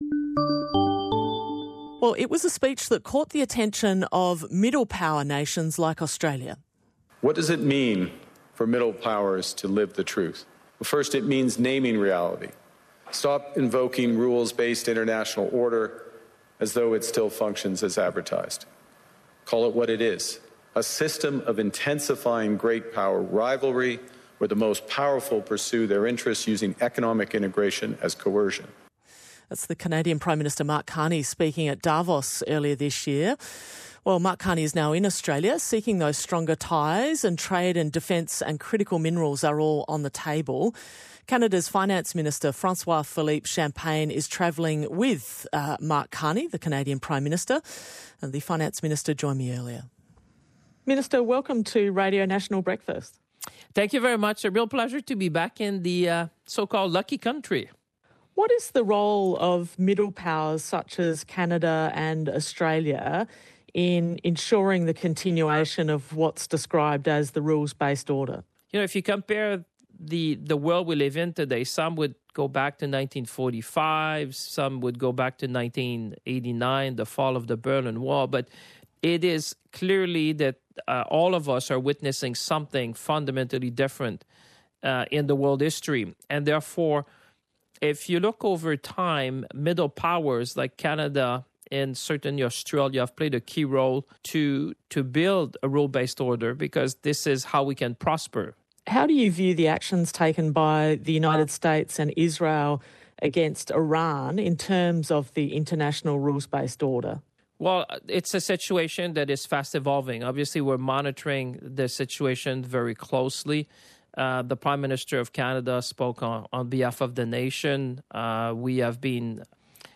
GUEST: François-Philippe Champagne, Canadian Finance Minister PRODUCER